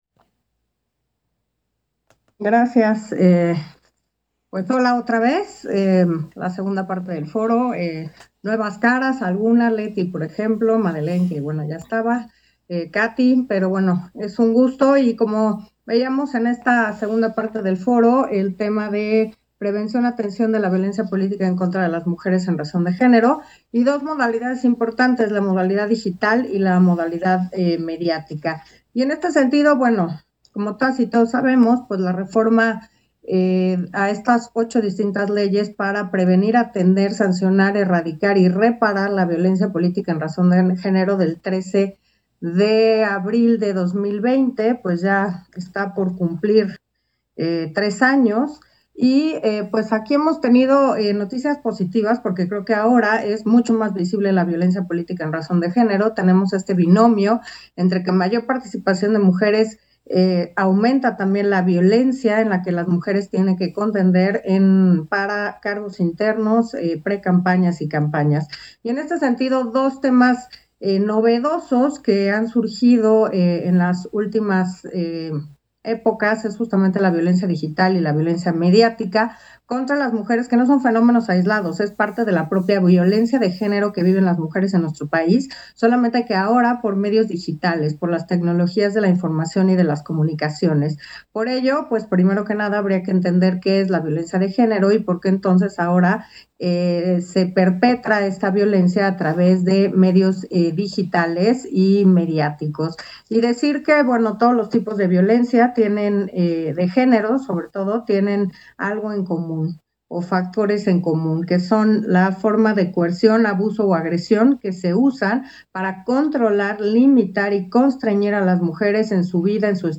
Intervención de Carla Humphrey, en la Ronda 2: Prevención y atención de la violencia política contra las mujeres en razón de género.